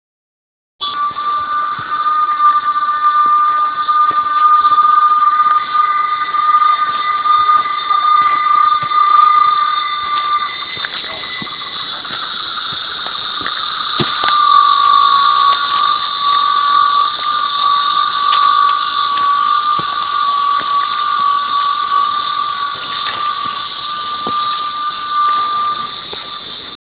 Le cicale da queste parti hanno una voce molto diversa dalle nostre.
cicale.wav